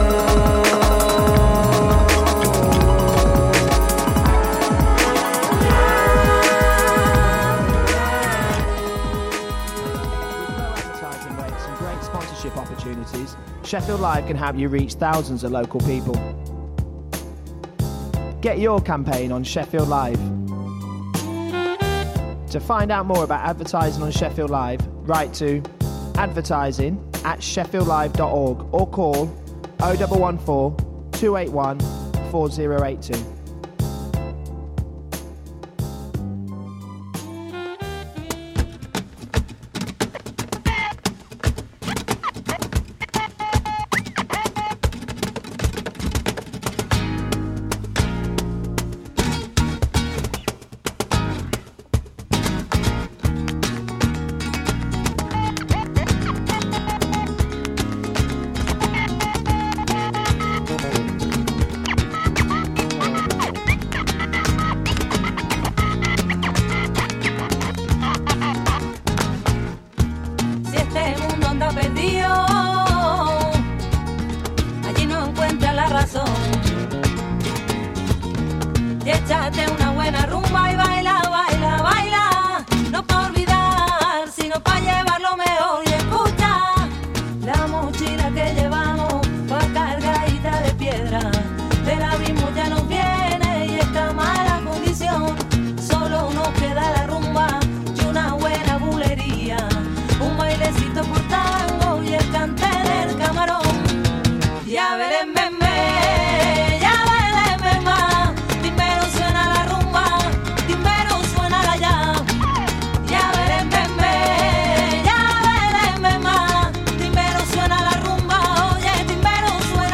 The very best new upfront music ahead of release dates & classic old tunes ranging round dub, chill, breaks, jungle, dnb, techno & anything else